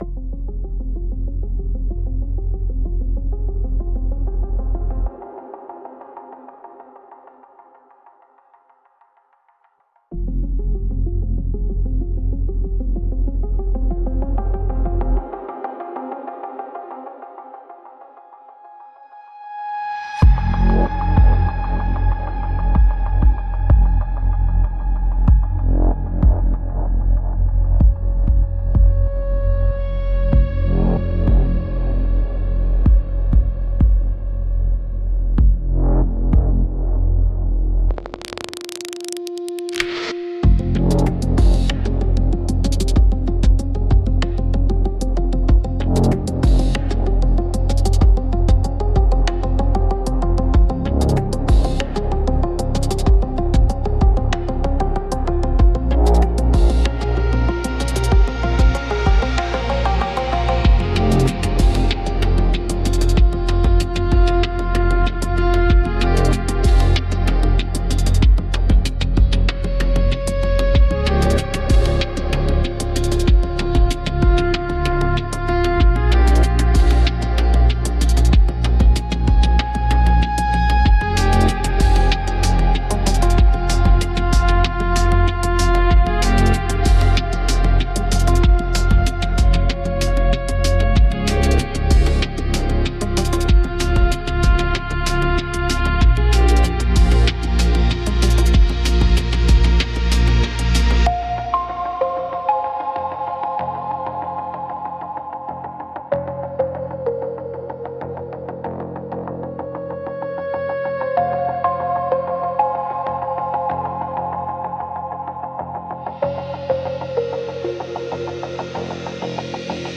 S O U N D S C A P E